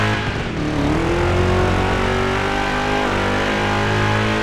Index of /server/sound/vehicles/sgmcars/buggy